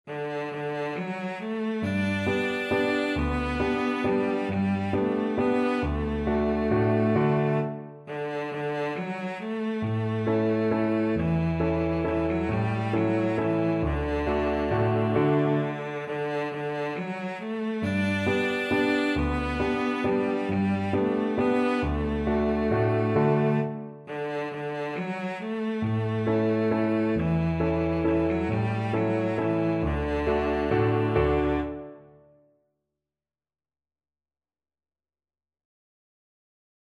One in a bar .=c.45
3/4 (View more 3/4 Music)